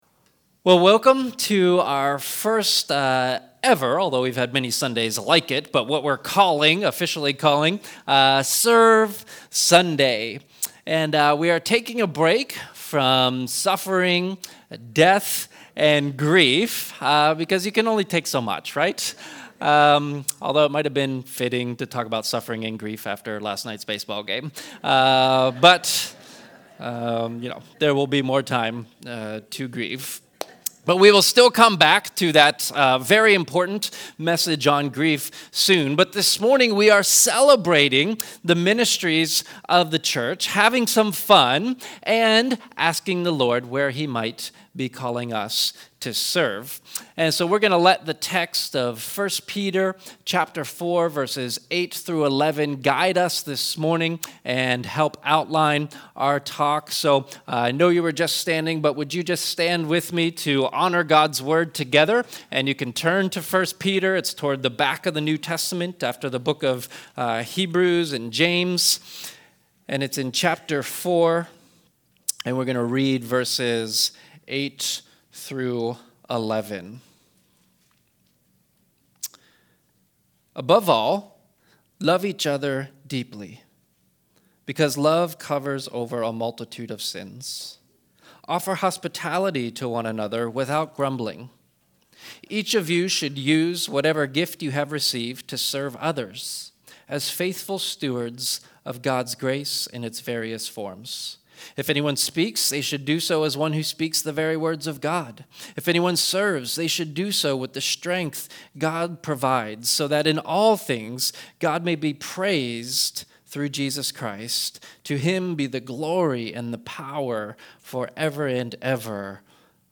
Our first ever SERVE SUNDAY message. Be inspired to love others more deeply and heed the Scriptural call to serve one another.